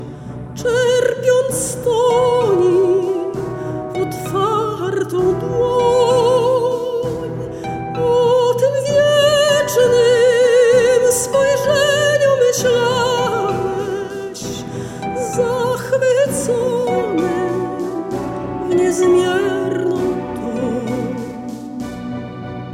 pieśniarka, instruktorka muzyki